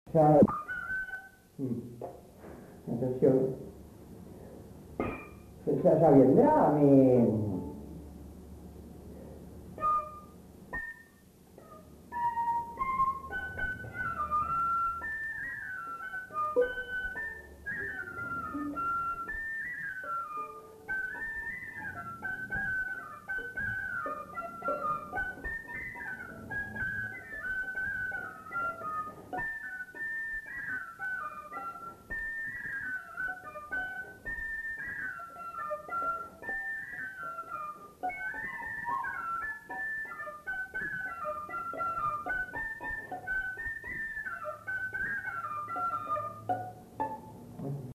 Polka piquée
Lieu : Bazas
Genre : morceau instrumental
Instrument de musique : fifre ; violon
Danse : polka piquée